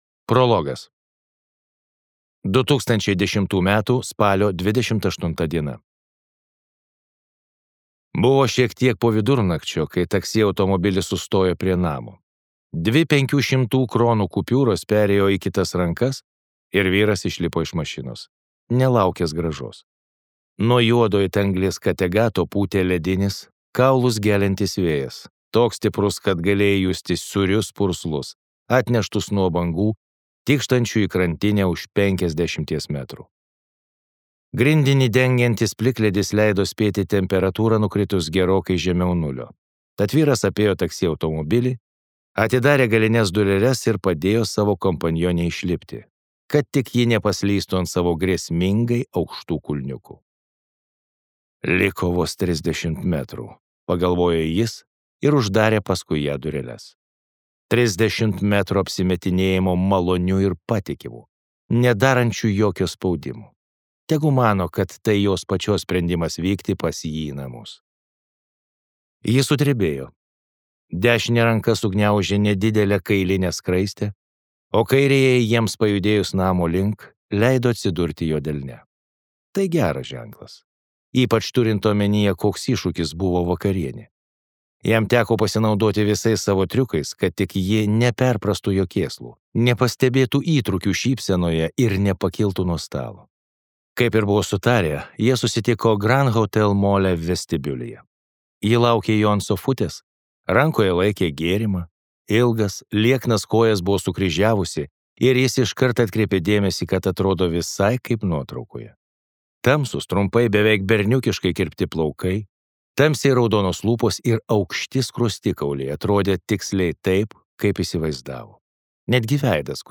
18 laipsnių šalčio | Audioknygos | baltos lankos